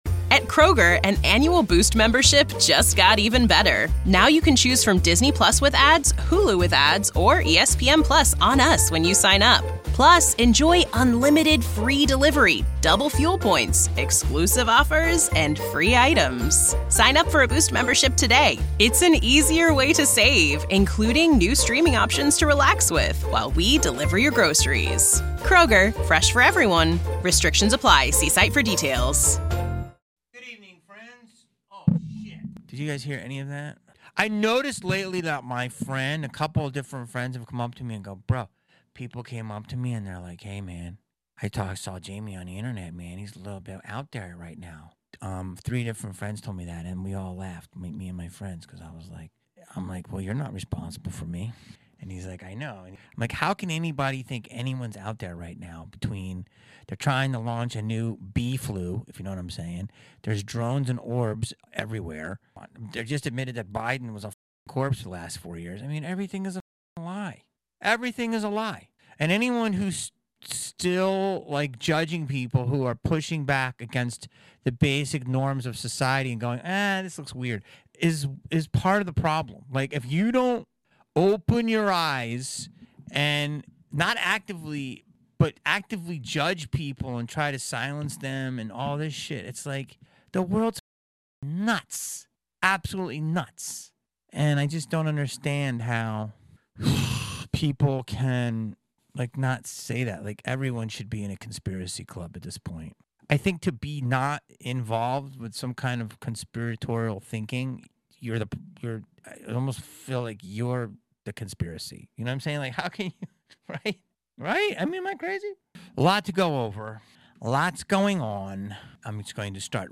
HATE TO BREAK IT TO YA is a weekly podcast hosted by actor/comedian Jamie Kennedy. Jamie is on a constant quest to get to the bottom of things.